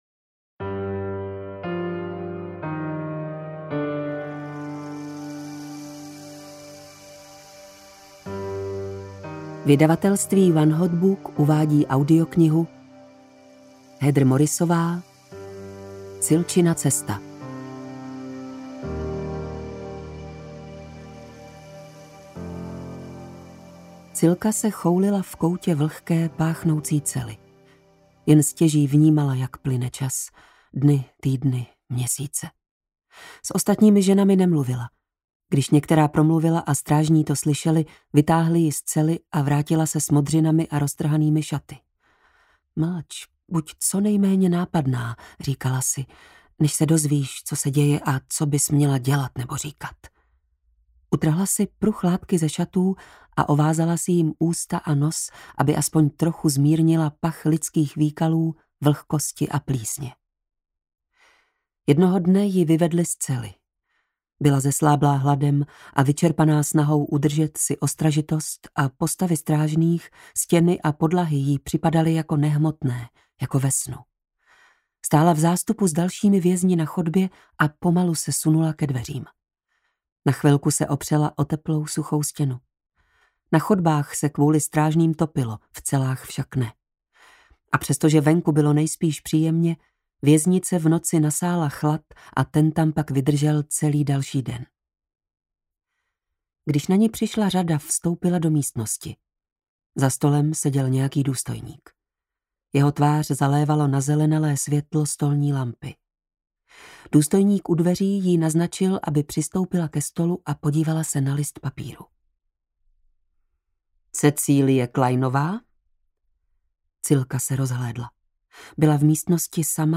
Cilčina cesta audiokniha
Ukázka z knihy
cilcina-cesta-audiokniha